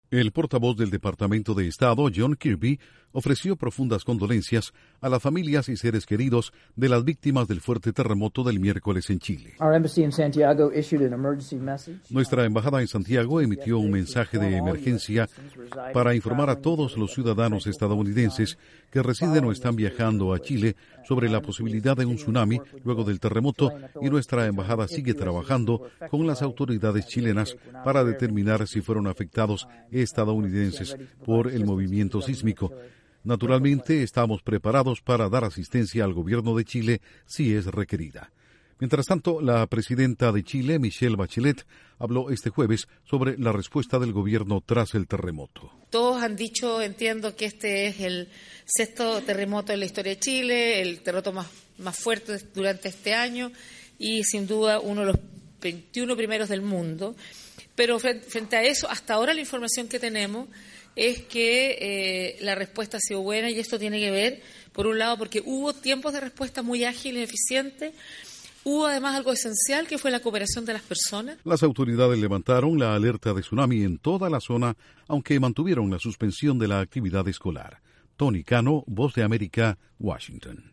Estados Unidos ofrece condolencias y asistencia a Chile luego del fuerte terremoto del miércoles. Informa desde la Voz de América en Washington